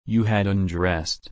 /ʌnˈdɹɛs/